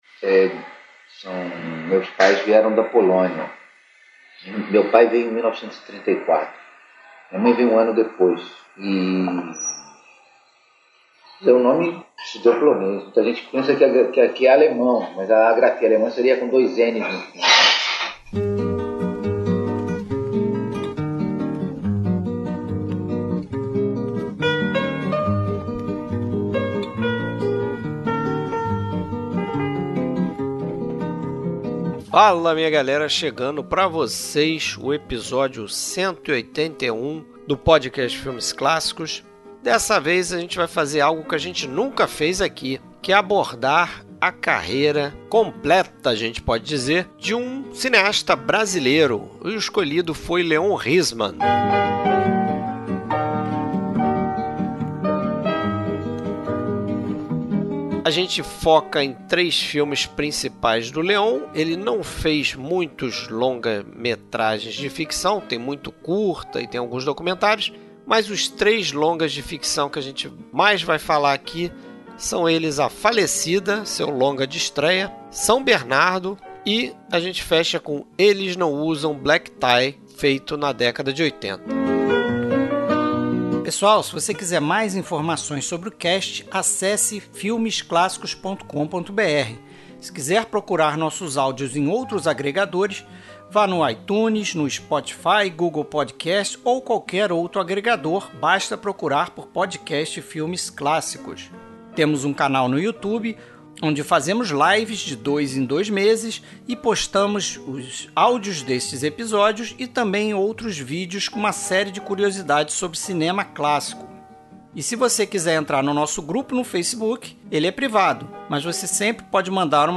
Trilha Sonora: Trilhas sonoras compostas para os filmes comentados.